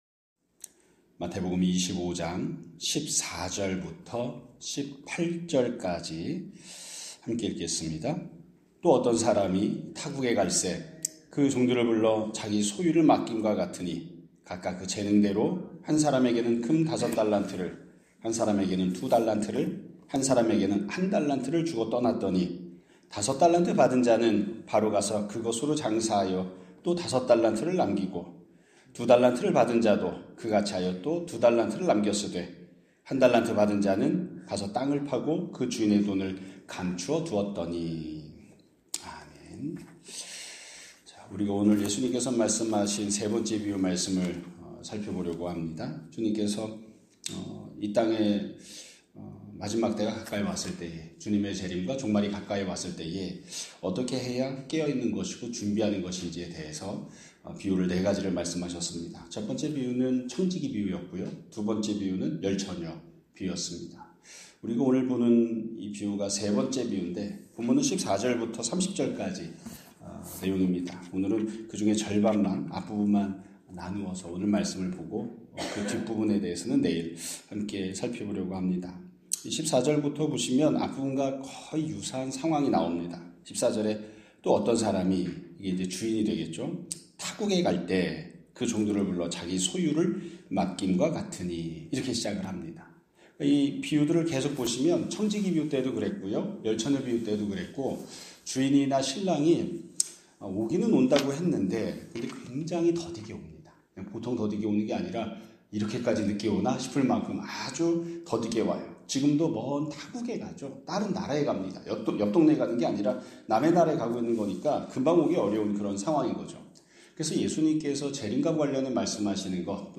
2026년 3월 19일 (목요일) <아침예배> 설교입니다.